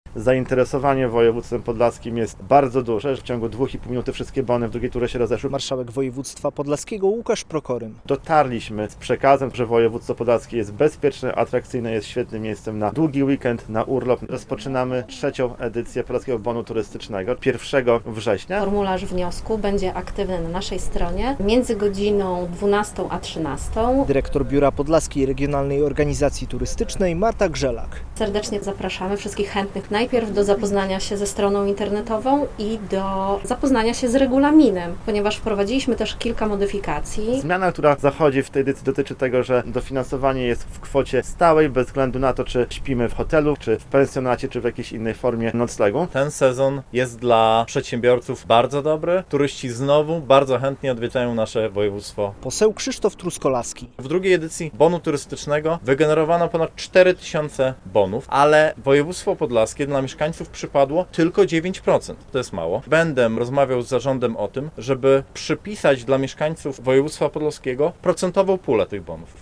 Podlaski Bon Turystyczny, nowe rozdanie - relacja
Zmiana, która zachodzi w tej edycji dotyczy tego, że dofinansowanie jest w kwocie stałej, bez względu na to, czy śpimy w hotelu, w pensjonacie czy innym noclegu - mówił na konferencji prasowej (21.08) marszałek województwa podlaskiego Łukasz Prokorym.